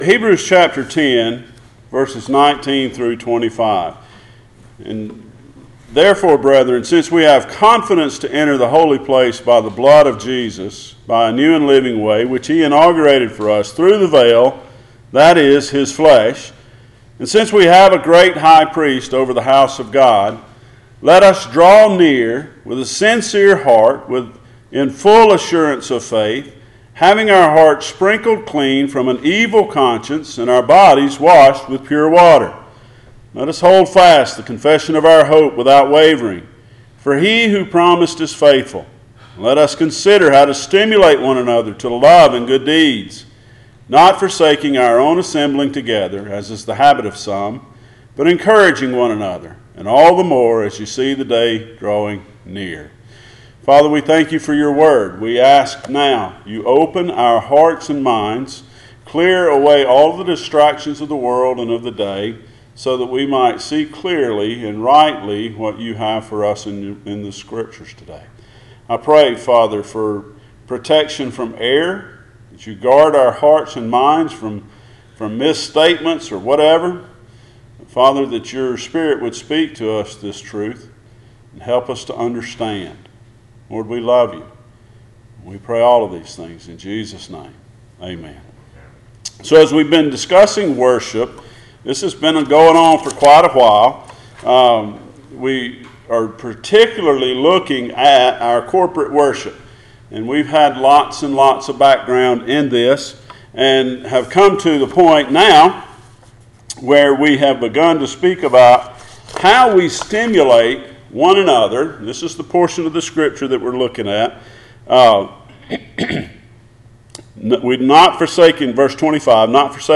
Passage: Hebrews 10:19-25 Service Type: Sunday Morning